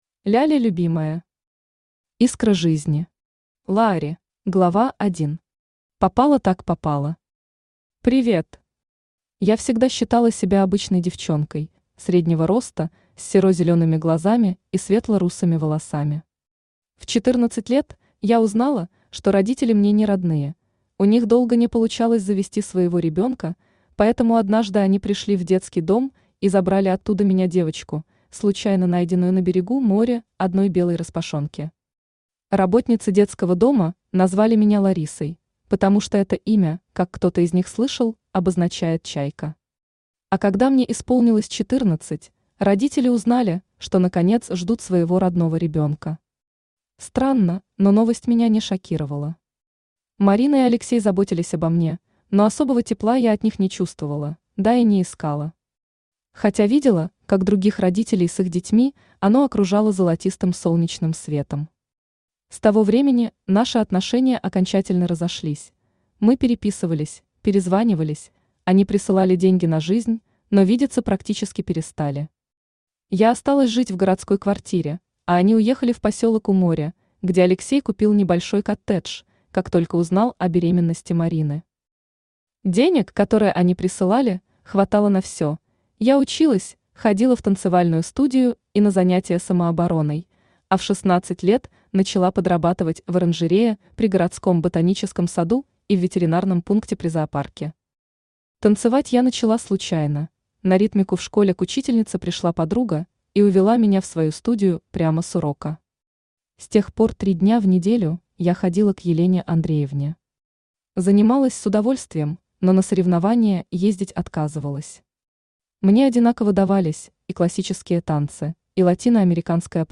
Aудиокнига Искра жизни. Лаари Автор Ляля Любимая Читает аудиокнигу Авточтец ЛитРес.